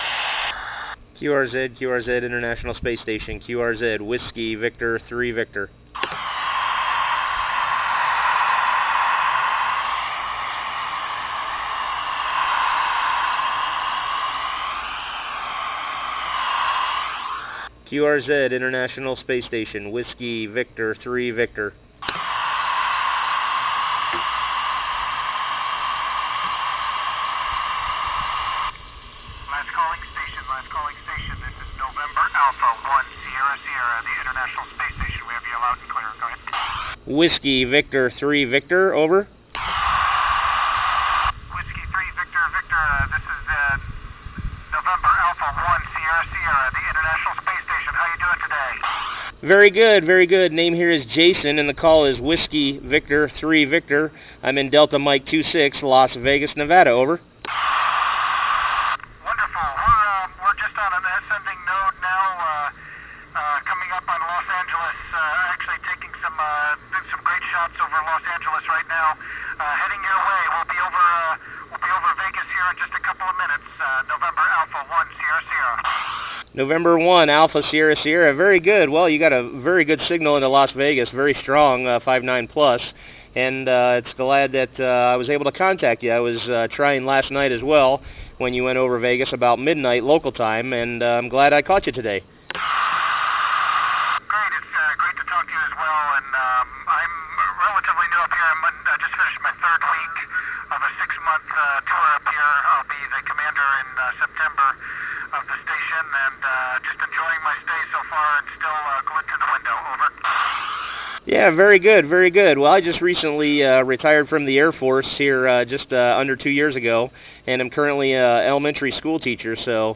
I was using my Yaesu VX-8r hand held, and the Arrow Dual band antenna.